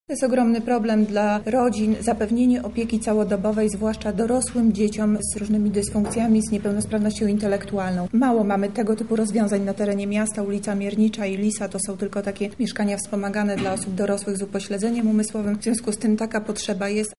– mówi Monika Lipińska, zastępca prezydenta Lublina.